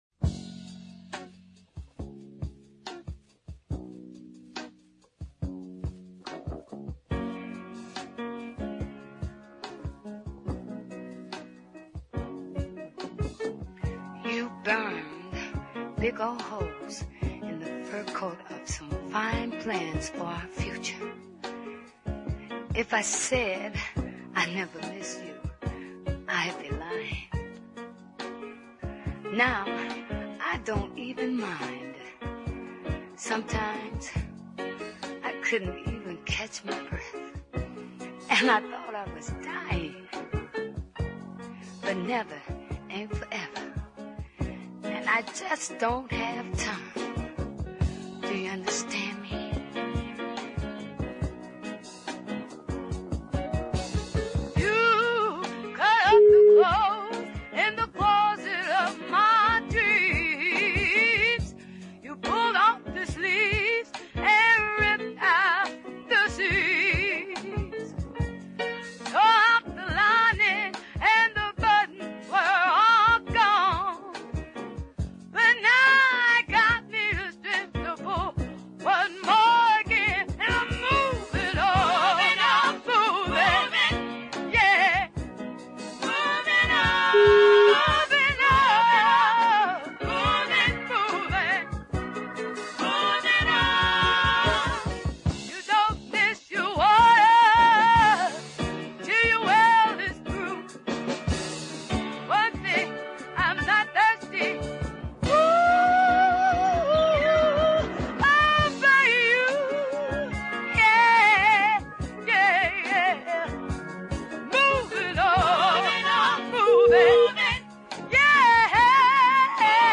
ballad
gospel styled vocal support